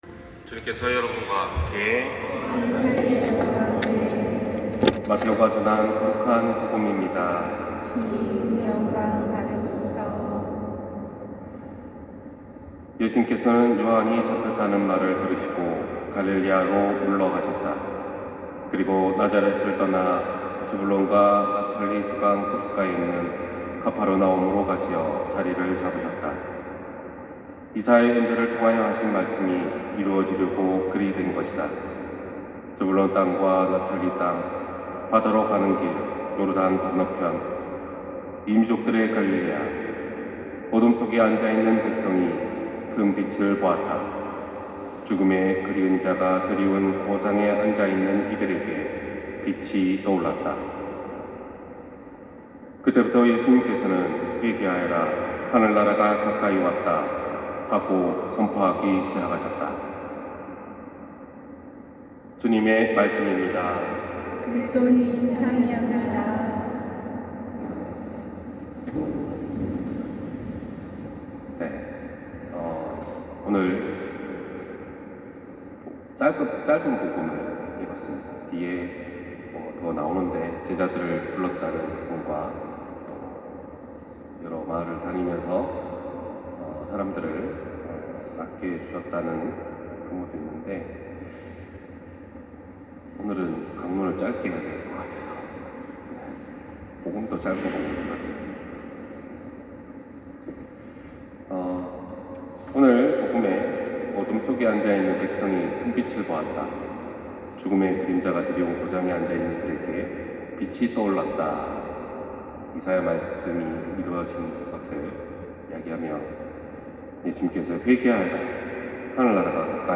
260126신부님 강론말씀